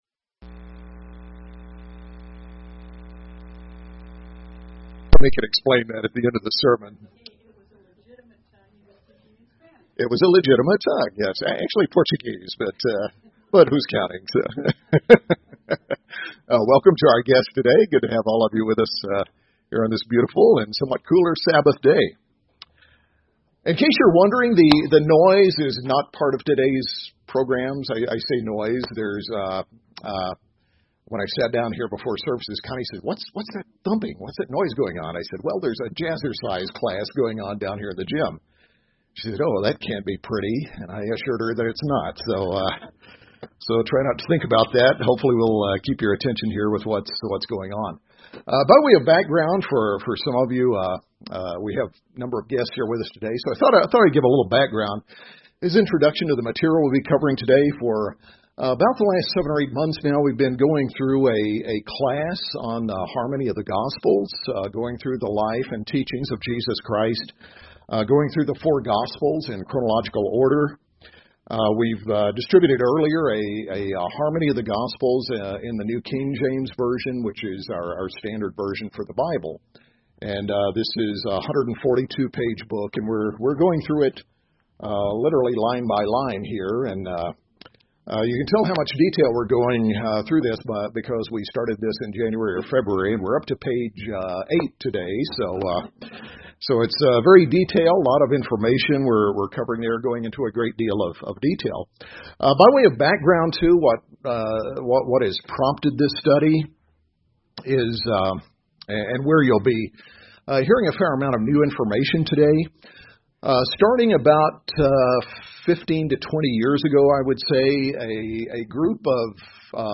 In this class, we discuss one of the Bible's more intriguing personalities, John the Baptizer. Many have misconceptions about him, viewing him as some sort of wild man in the desert doing strange things, wearing strange clothing and eating strange things.